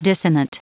Prononciation du mot dissonant en anglais (fichier audio)
Prononciation du mot : dissonant